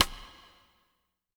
BW STICK01-R.wav